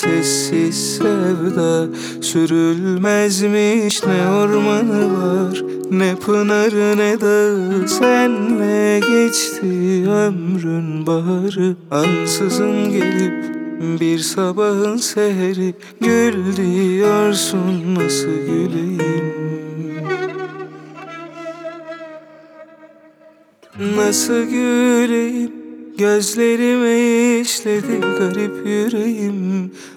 Жанр: Поп / Турецкая поп-музыка